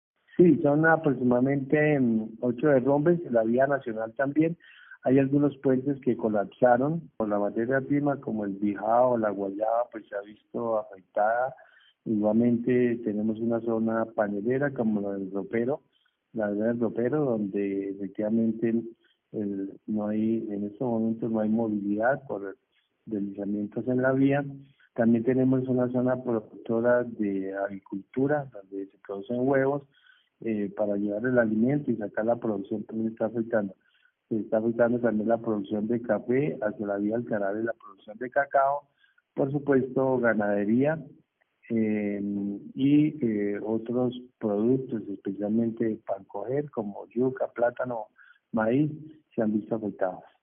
Orlando Ariza Ariza, Alcalde de Vélez, Santander
Escuche aquí al Alcalde de Vélez: